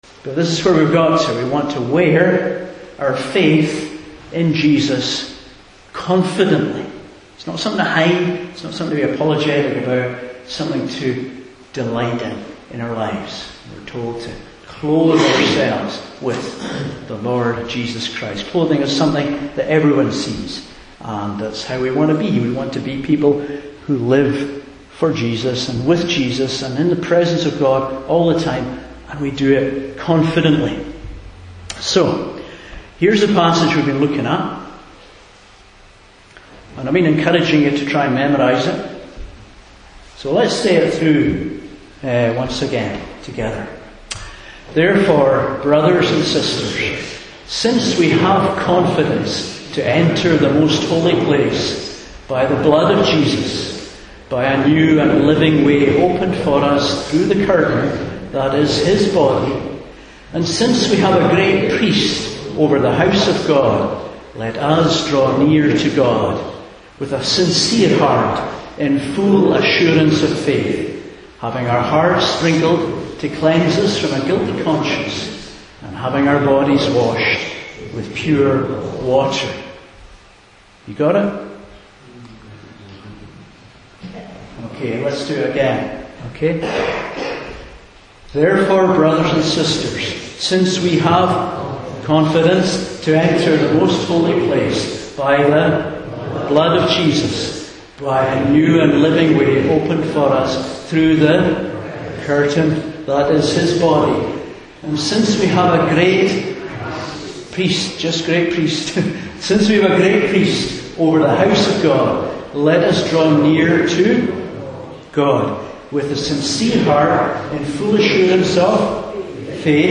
Hebrews 10:19-25 Service Type: Morning Service Why have we spent several weeks learning about the priesthood and sacrifice of Jesus?